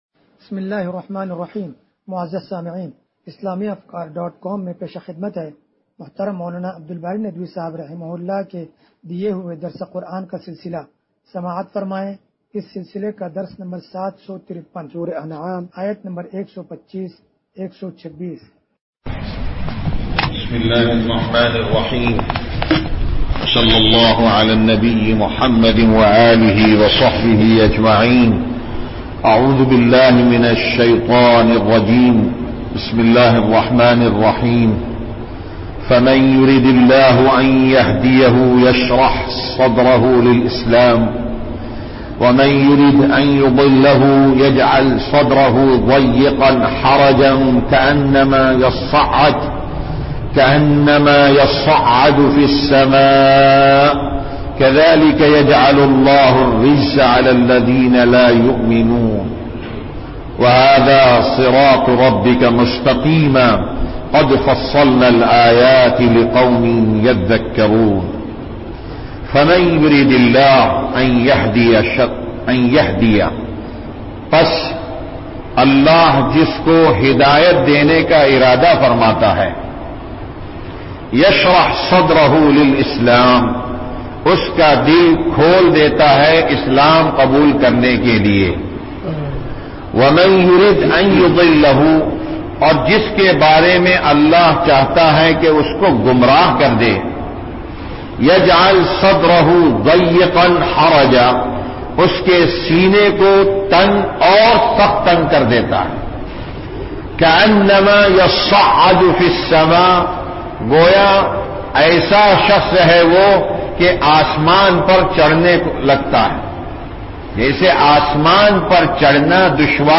درس قرآن نمبر 0753
درس-قرآن-نمبر-0753.mp3